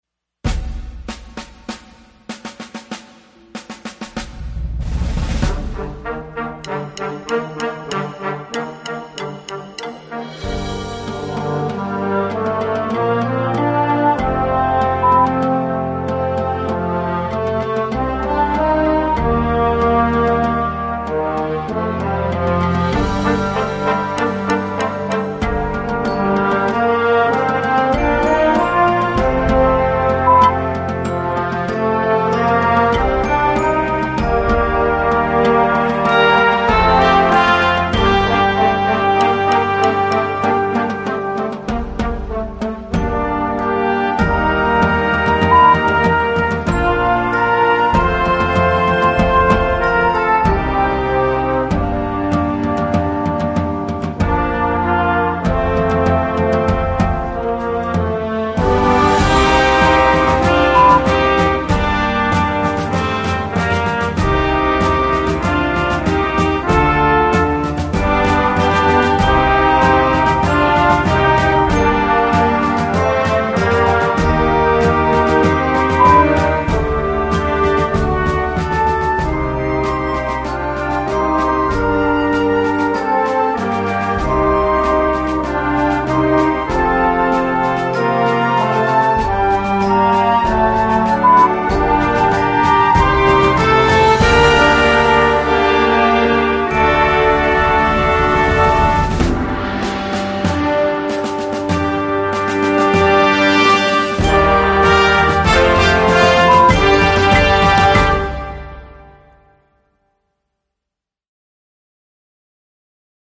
Besetzung: Blasorchester
powerful and majestic
here is a terrific yet easy arrangement of the main themes.